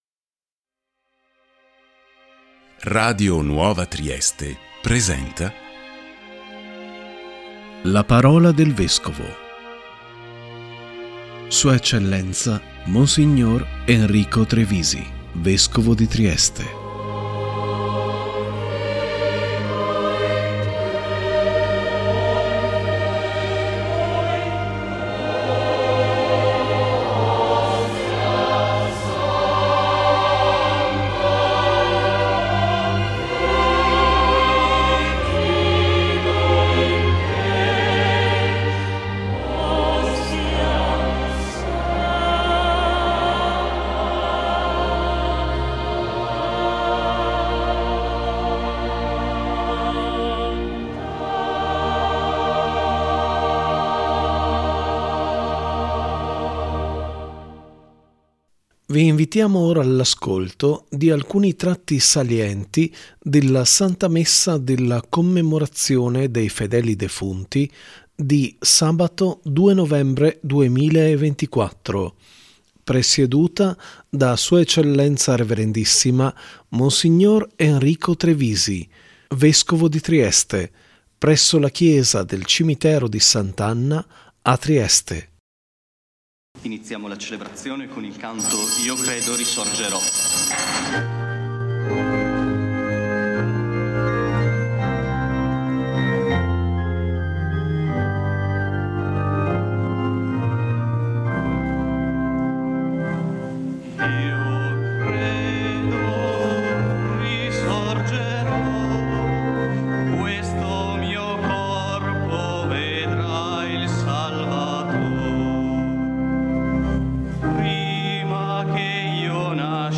♦ si è tenuta Sabato 2 novembre 2024, la Santa Messa della Commemorazione dei fedeli defunti presieduta da S.E. Rev.issima Mons. Enrico Trevisi, Vescovo di Trieste, presso la chiesa del Cimitero di S.Anna a Trieste.